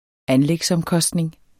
Udtale [ ˈanlεgs- ]